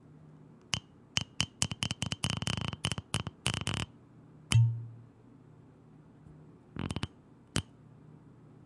软木
描述：在瓶子里拧开一个软木塞。
标签： 软木 扭曲 酒瓶
声道立体声